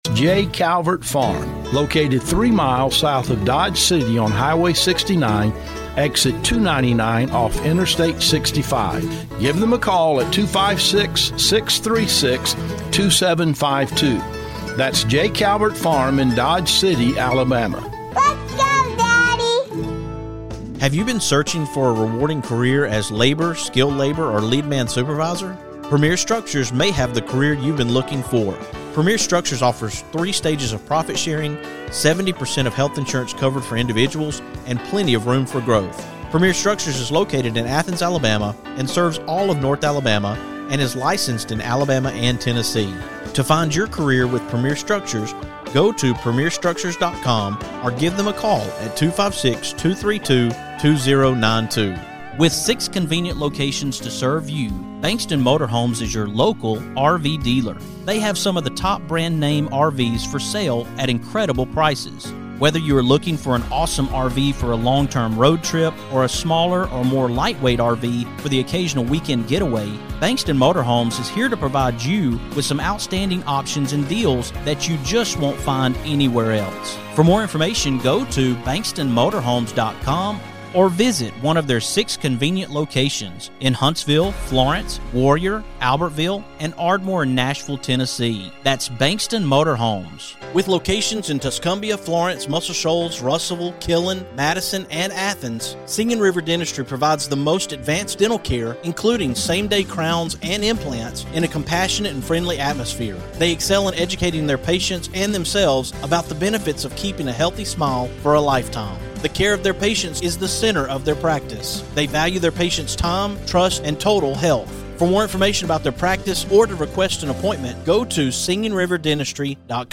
This is an interview that will remind you of the power of resilience, the importance of belonging, and the impact of stepping up for others.